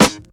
• 00's Original Hip-Hop Steel Snare Drum Sound G# Key 41.wav
Royality free snare sound tuned to the G# note. Loudest frequency: 2125Hz